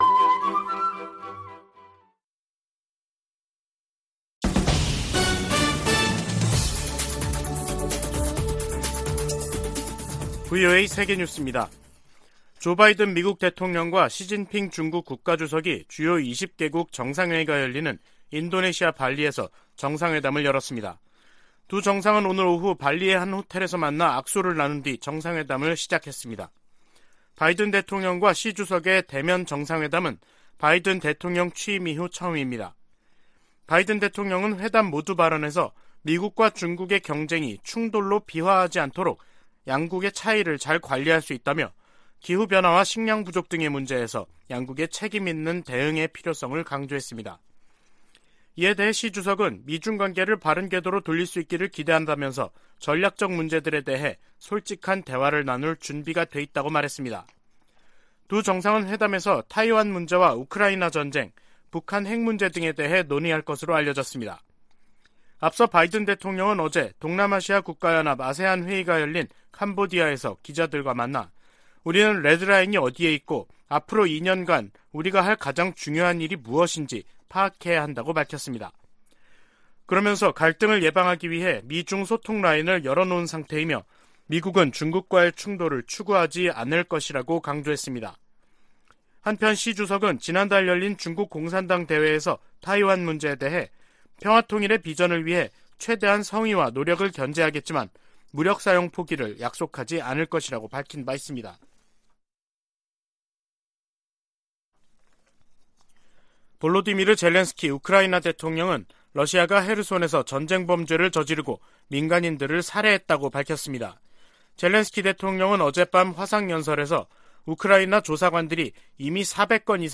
VOA 한국어 간판 뉴스 프로그램 '뉴스 투데이', 2022년 11월 14일 2부 방송입니다. 미국과 한국, 일본 정상들이 미한일 정상이 13일 캄보디아에서 만나 북한 문제와 관련해 억제력을 강화하기 위해 협력하기로 합의했습니다. 미,한,일 정상의 ‘프놈펜 공동성명’은 북한의 고조된 핵 위협에 대응한 세 나라의 강력한 공조 의지를 확인했다는 평가가 나오고 있습니다.